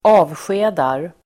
Uttal: [²'a:vsje:dar]